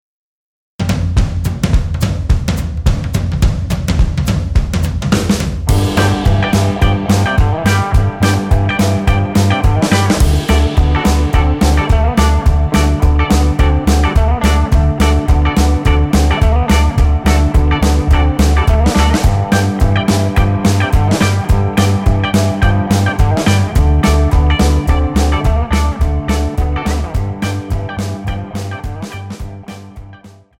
--> MP3 Demo abspielen...
Tonart:G ohne Chor